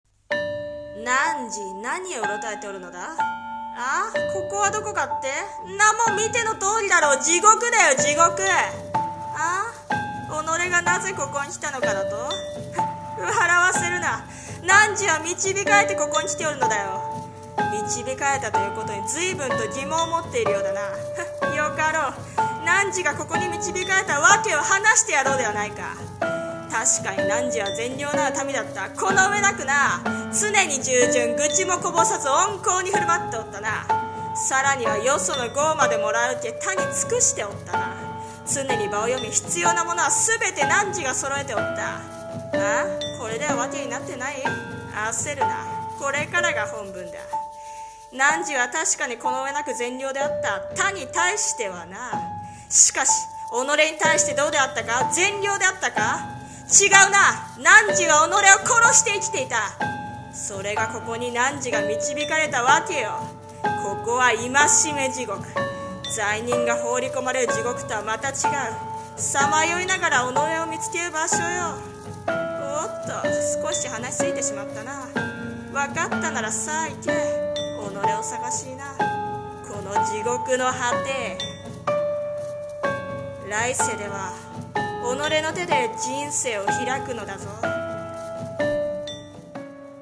さんの投稿した曲一覧 を表示 【声劇台本】戒め地獄